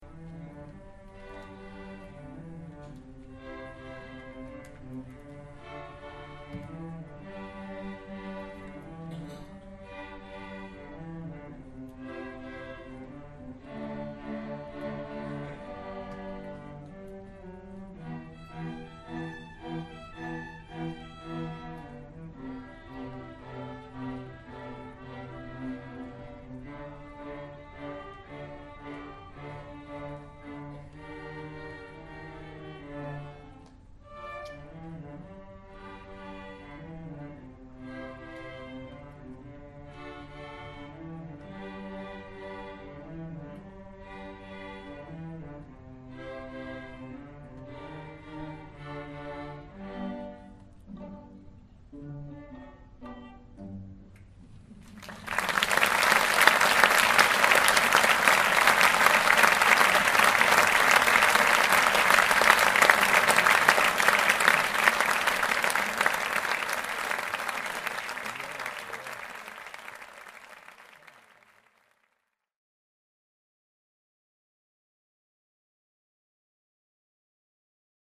Strawberry Flip - String Ensemble